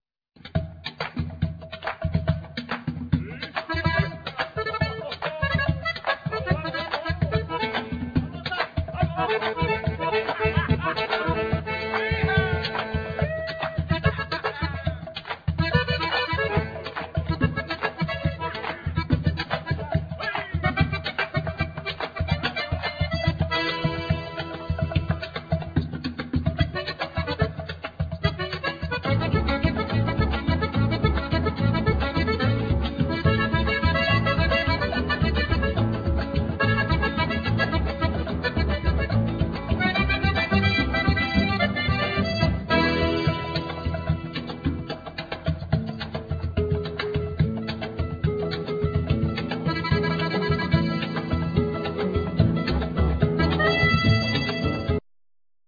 Accordion,Vocal,Percussions
Harp,Percussions
Bandoneon
Guitar
Double bass